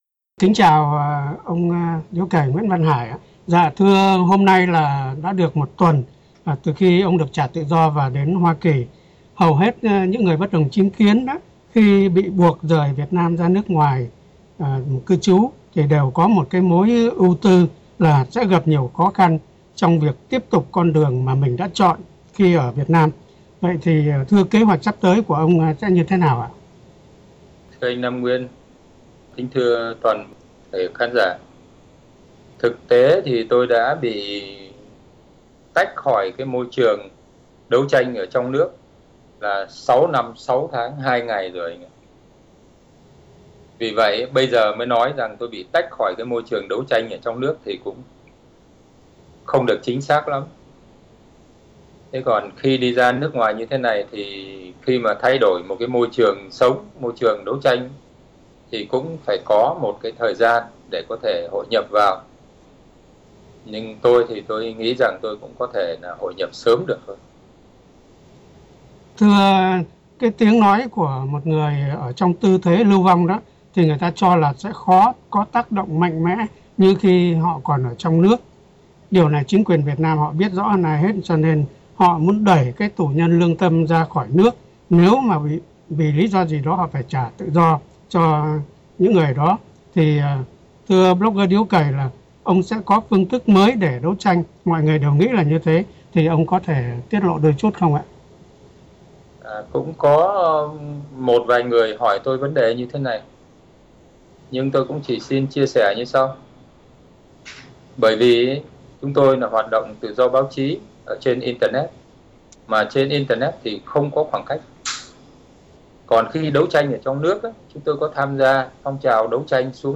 Phỏng Vấn – Sáng Tạo
phỏng vấn blogger Điếu Cày qua Skype chiều 27/10/2014